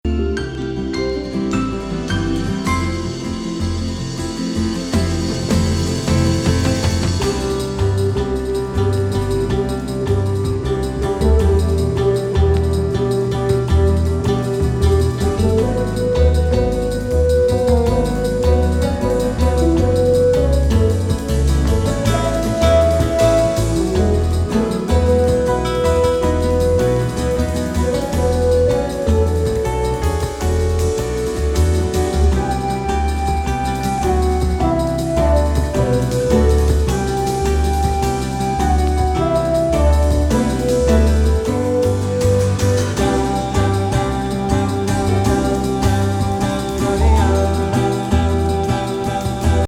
ドラマチック&壮大なスケールのLOFT CLASSIC